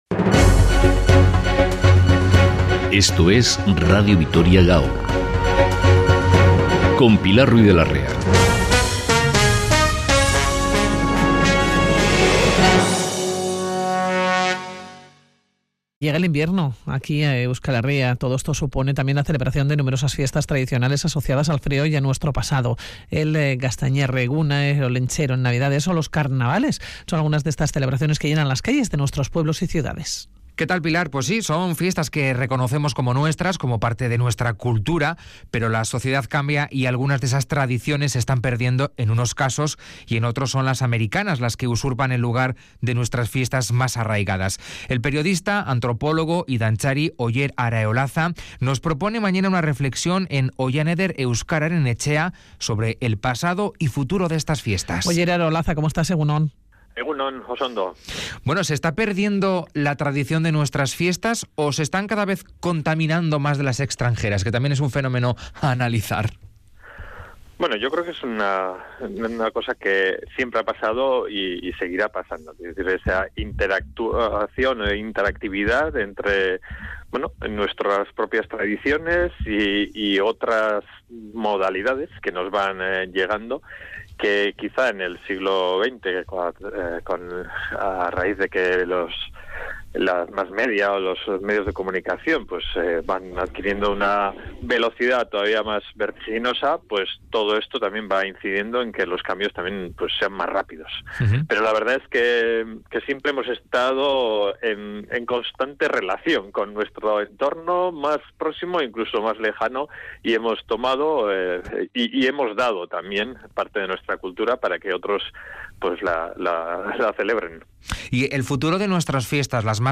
Oihaneder Euskararen Etxea ha organizado una charla